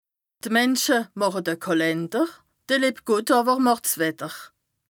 Bas Rhin
Ville Prononciation 67
2APRESTA_OLCA_LEXIQUE_METEO_AIR_BAS_RHIN_417_0.mp3